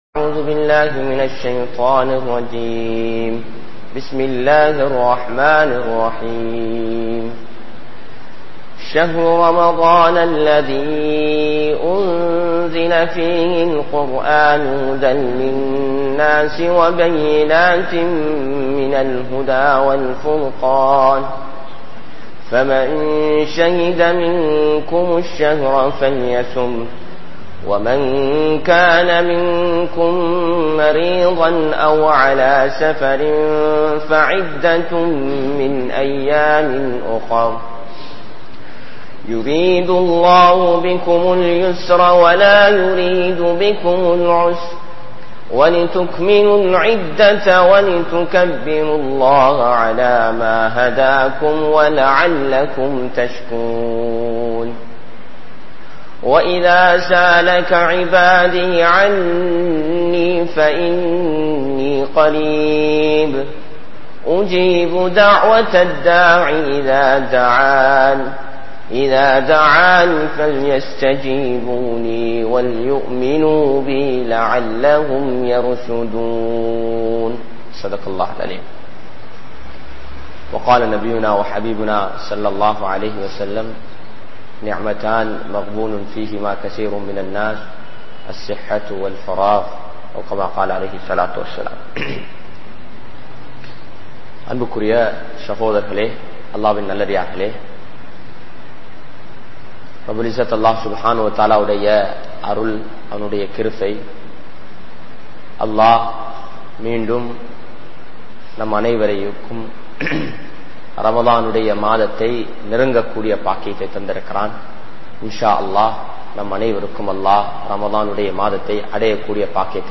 Let`s Welcome Ramalan | Audio Bayans | All Ceylon Muslim Youth Community | Addalaichenai